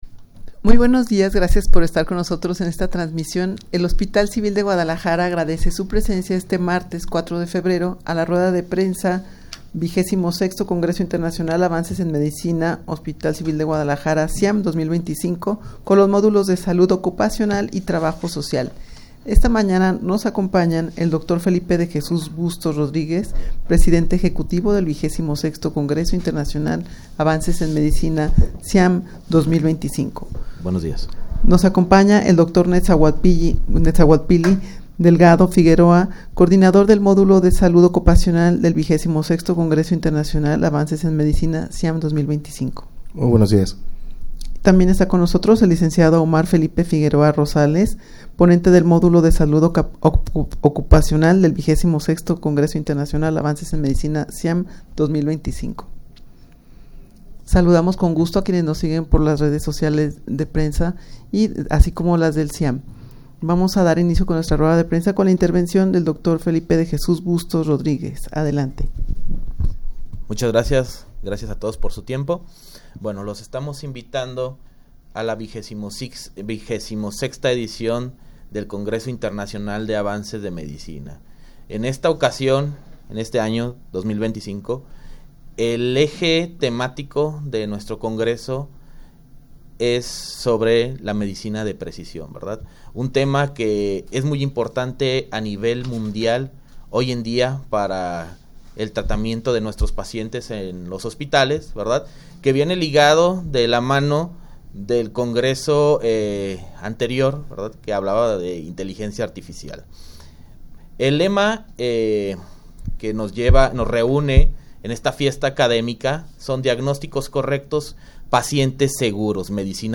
Audio de la Rueda de Prensa
rueda-de-prensa-ciam-2025-con-los-modulos-de-salud-ocupacional-y-trabajo-social.mp3